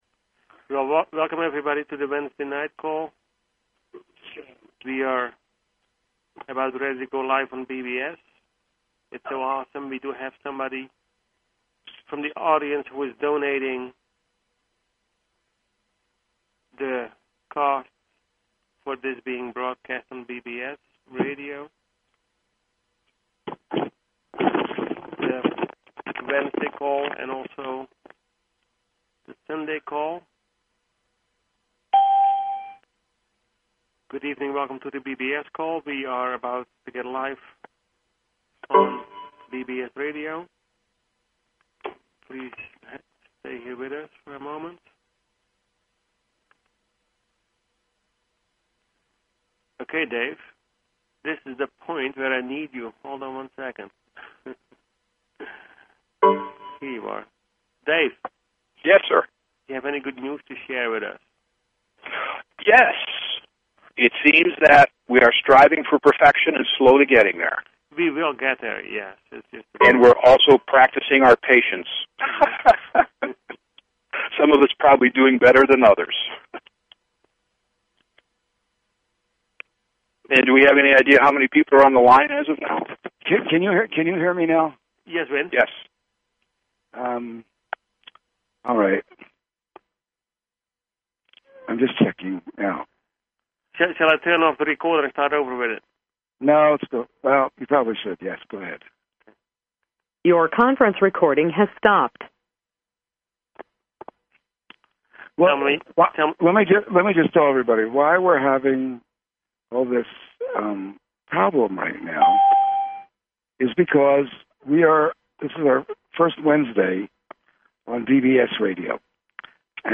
Call In Show
Talk Show